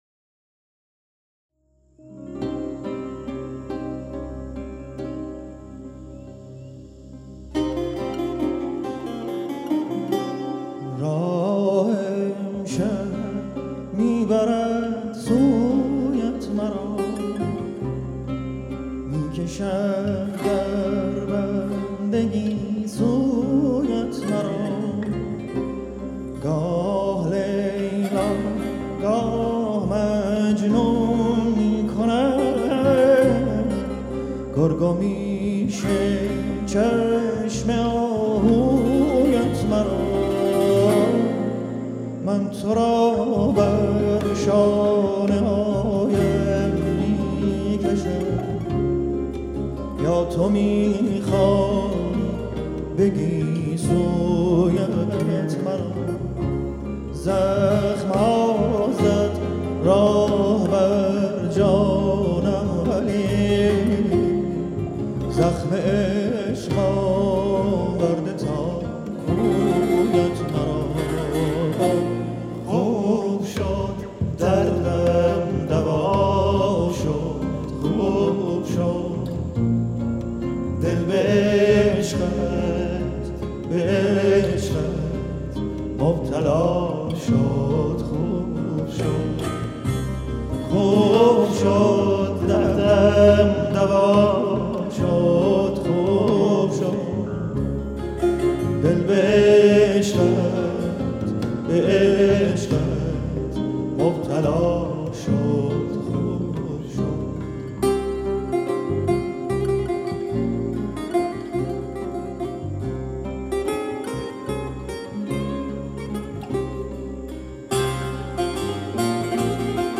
khob shod live concerte 30(2).mp3